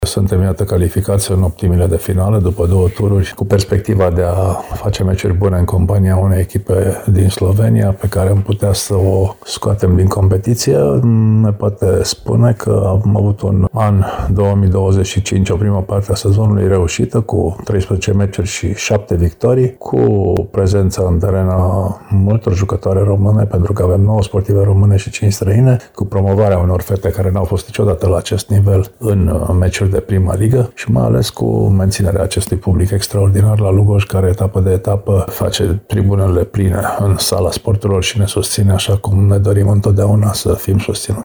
Într-un interviu pentru Radio Reşiţa, oficialul grupării timişene este mulţumit şi pentru faptul că echipa din Lugoj este, din nou, angrenată în cupele europene şi vorbeşte şi despre alt plus din acest sezon: folosirea a nouă jucătoare autohtone pe aproape toată durată stagiunii: